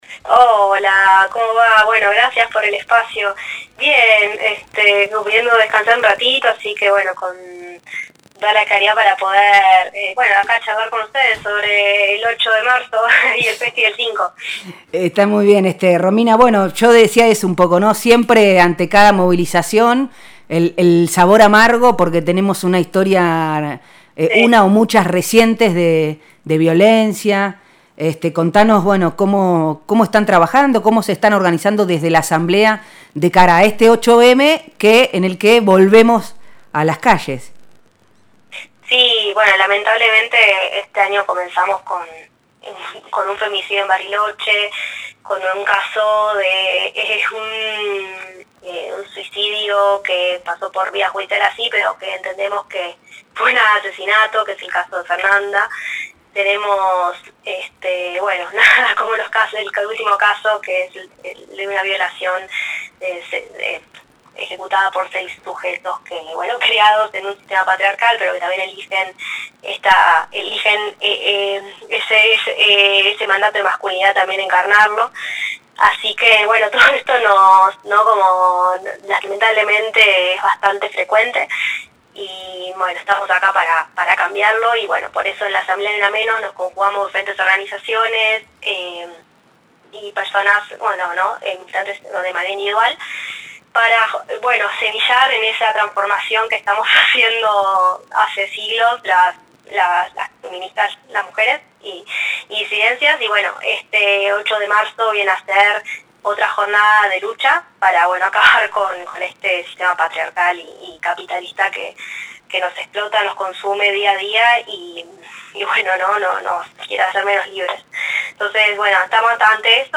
en diálogo con Nosotres les Otres